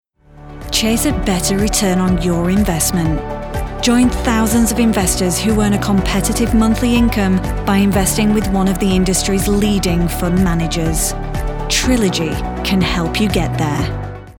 British VO Pro
Corporate Advert